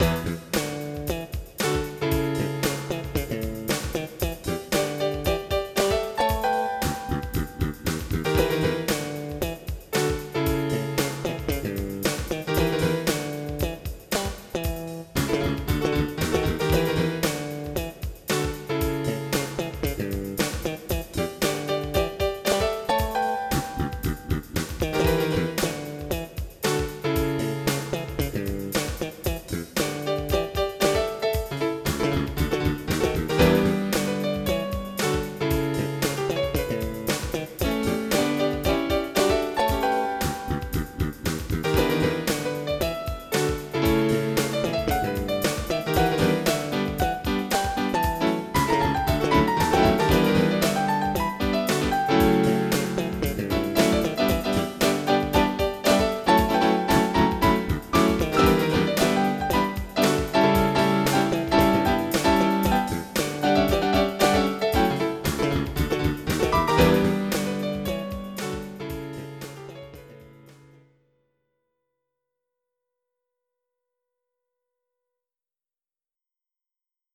MIDI Music File
Type General MIDI
funk.mp3